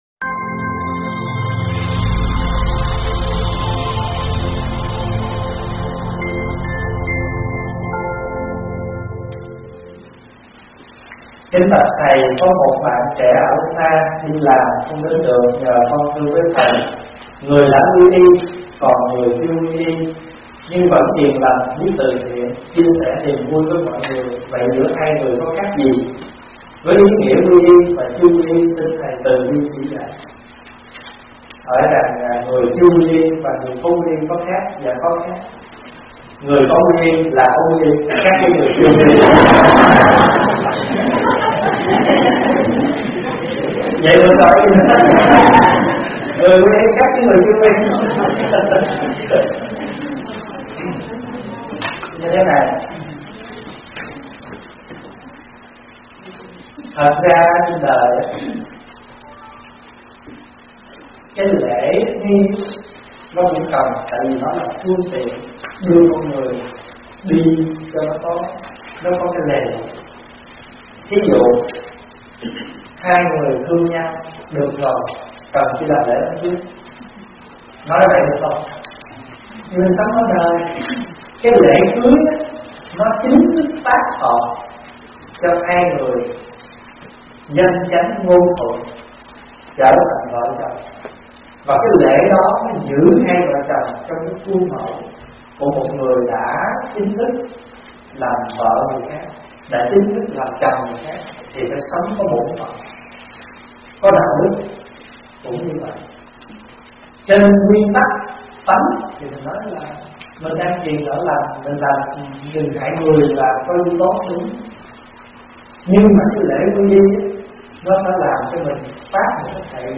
Mời quý phật tử nghe vấn đáp Khác Biệt và Ý Nghĩa của Quy Y do ĐĐ. Thích Pháp Hòa giảng
Mp3 Thuyết Pháp     Thuyết Pháp Thích Pháp Hòa     Vấn đáp Phật Pháp